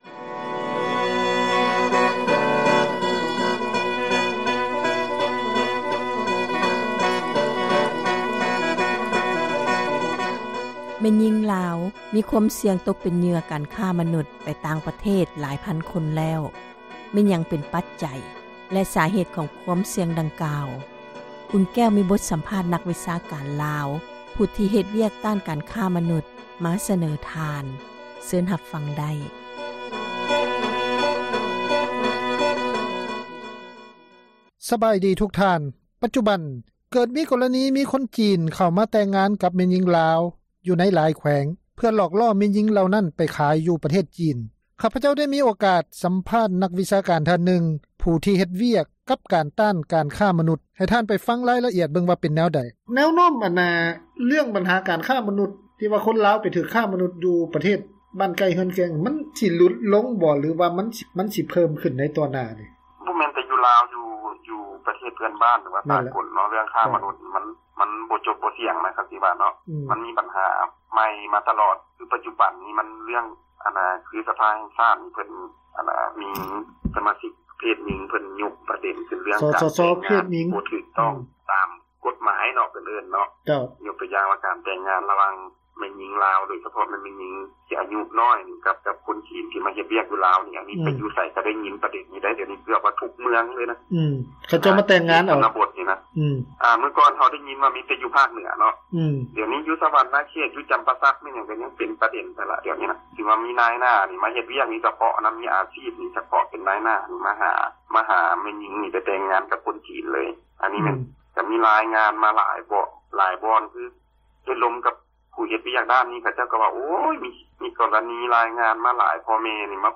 ມີບົດສຳພາດ ນັກວິຊາການລາວ ຜູ້ທີ່ເຮັດວຽກ ຕ້ານຄ້າມະນຸດ ມາສະເໜີ ທ່ານ